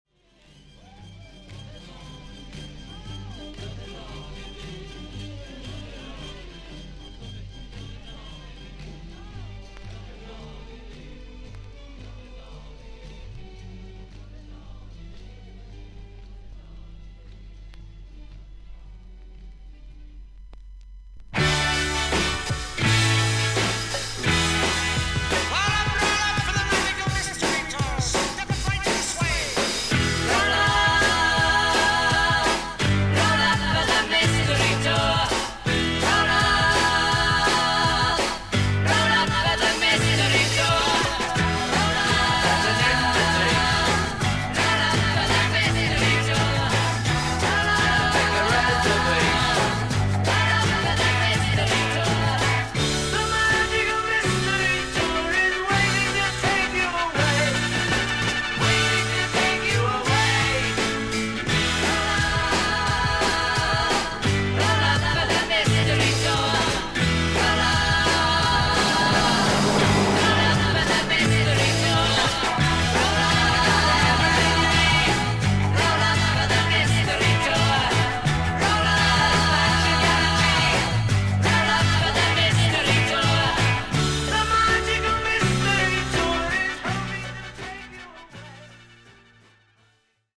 B面の最後に引っかきキズあり。フェードアウト部分に数回パチノイズあり。
ほかはVG++〜VG+:少々軽いパチノイズの箇所あり。クリアな音です。